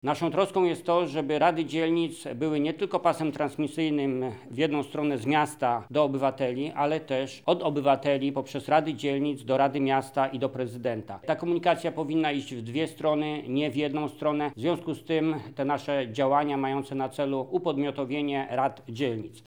• mówi senator Jacek Bury, członek koła parlamentarnego Polska 2050.